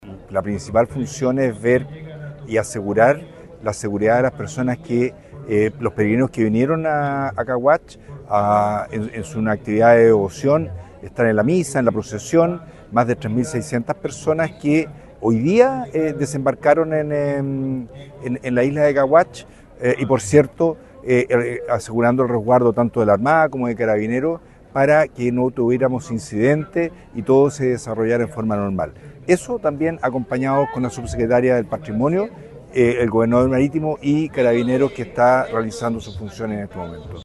Otros balances positivos de esta festividad se entregó por parte del delegado provincial, Marcelo Malagueño.